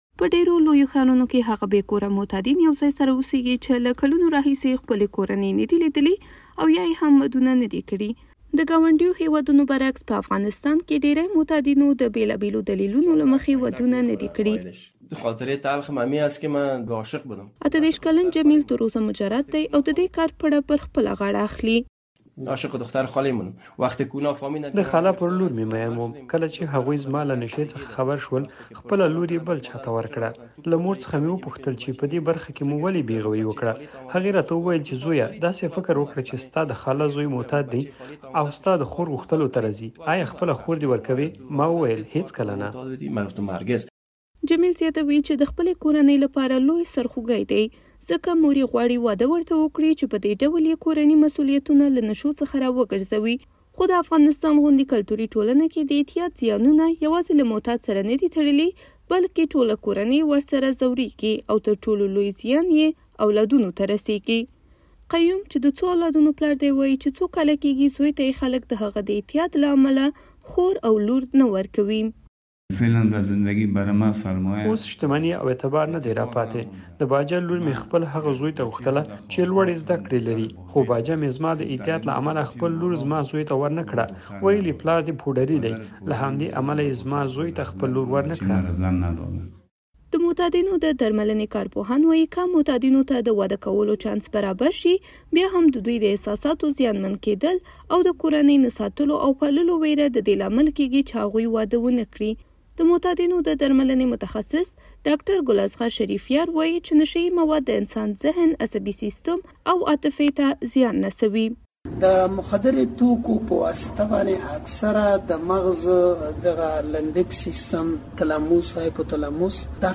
د ځوانانو ودونو په اړه راپور